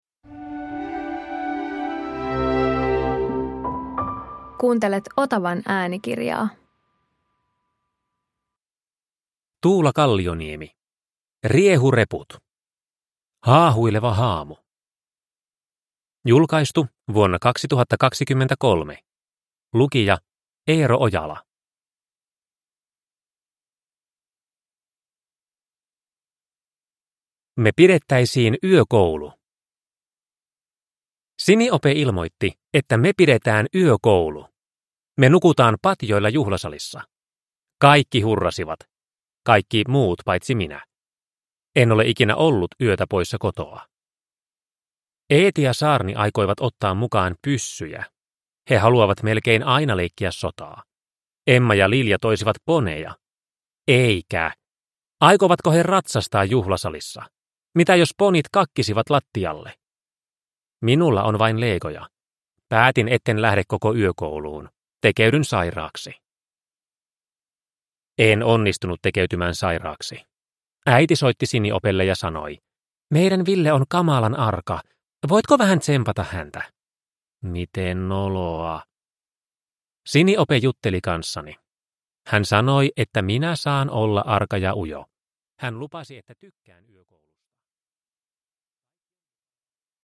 Riehureput – Haahuileva haamu – Ljudbok – Laddas ner